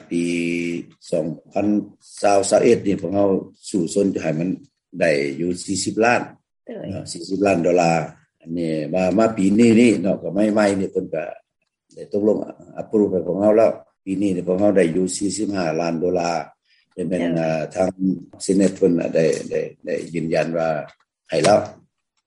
ສຽງ ທ່ານທູດຄຳພັນ ອັ່ນລາວັນກ່າວກ່ຽວກັບການຊ່ວຍເຫລືອຂອງ ສຫລ ແກ່ລາວໃນເລື້ອງ UXO